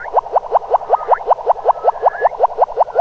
Category 🌿 Nature
ambient animal bird birdsong bubble delay dub echo sound effect free sound royalty free Nature